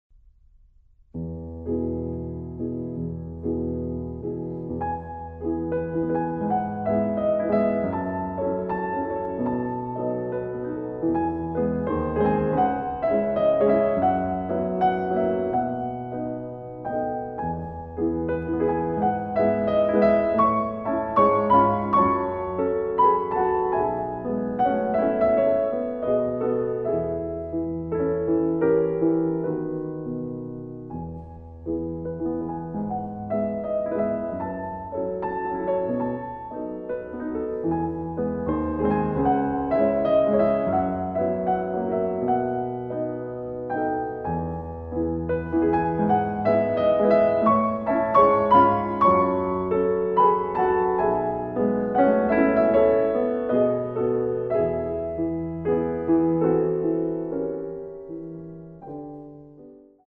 알림음